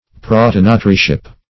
Prothonotaryship \Pro*thon"o*ta*ry*ship\, n. Office of a prothonotary.
prothonotaryship.mp3